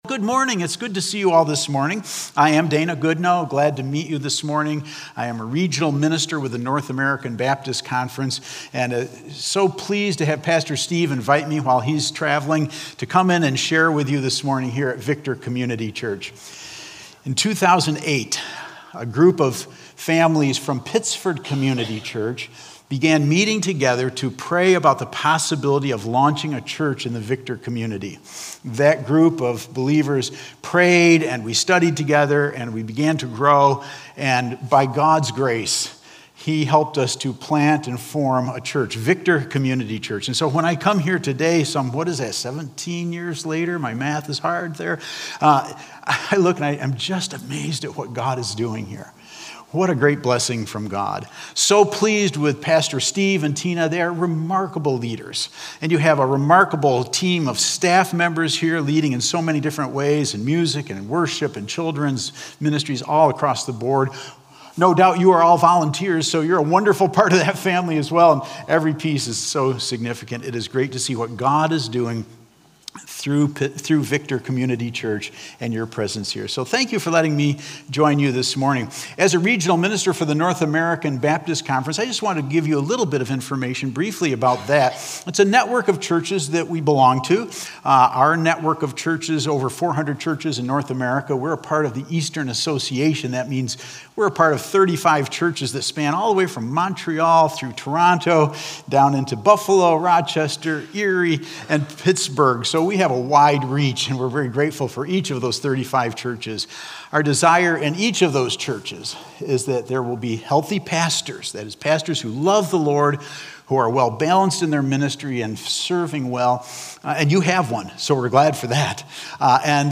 Victor Community Church Sunday Messages / Rooted: Finding Hope In Our Suffering (Oct 5th, 2025)